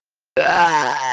president message